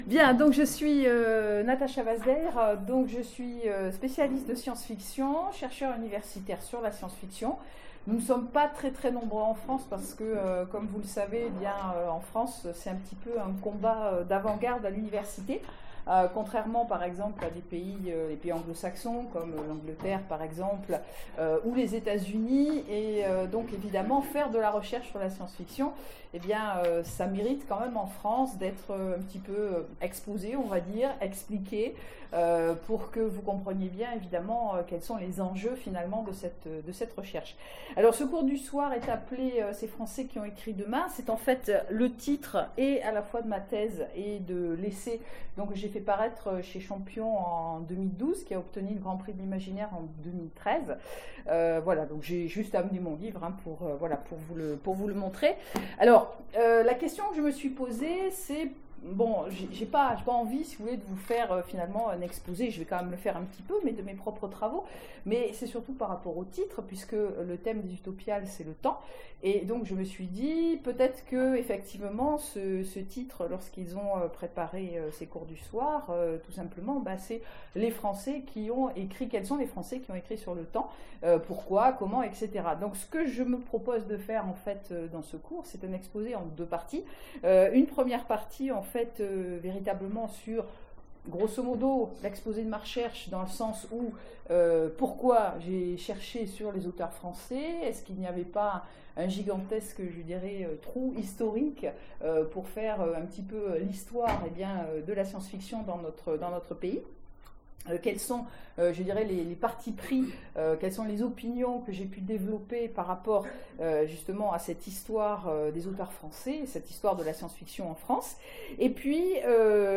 Mots-clés SF Conférence Partager cet article